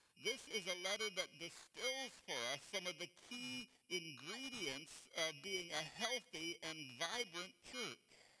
Audio glitching with focusrite 2i2
If something hogs the CPU & data bus for a few milliseconds too long your buffer overflows and you get a glitch.